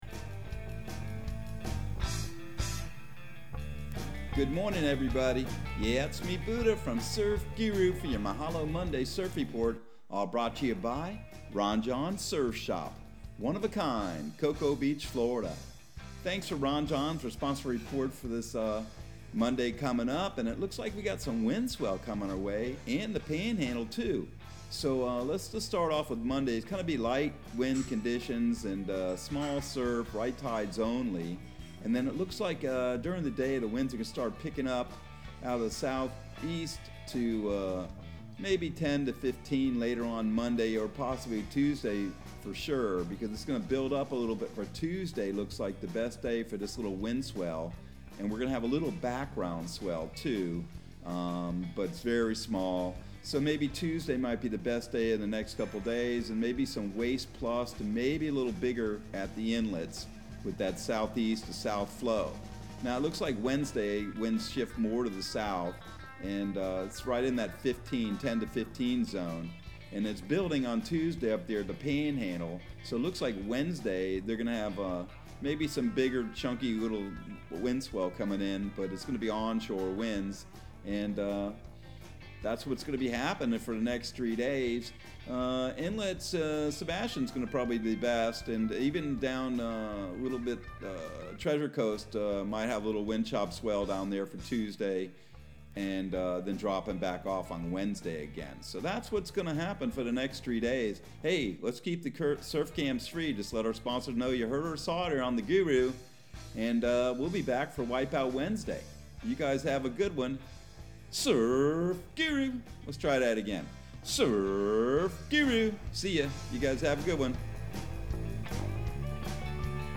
Surf Guru Surf Report and Forecast 04/04/2022 Audio surf report and surf forecast on April 04 for Central Florida and the Southeast.